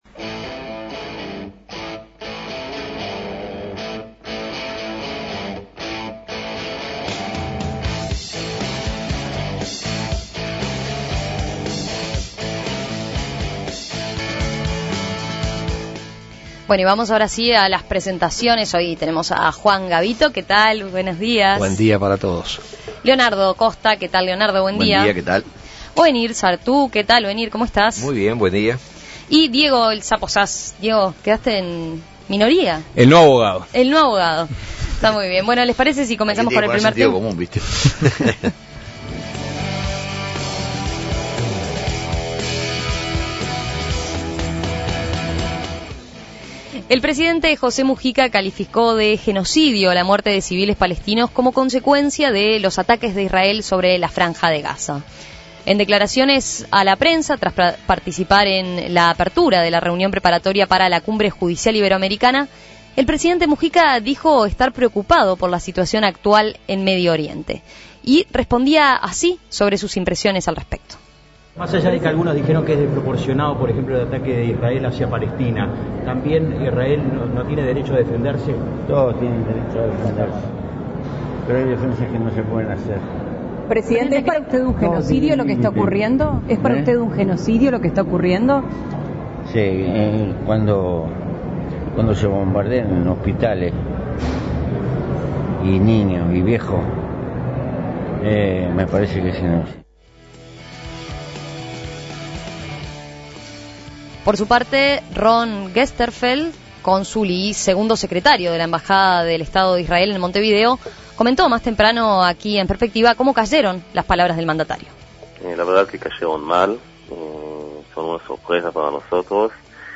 En declaraciones a la prensa tras participar en la apertura de la reunión preparatoria para la XVIII Cumbre Judicial Iberoamericana, el presidente Mujica dijo estar preocupado por la situación actual en Medio Oriente.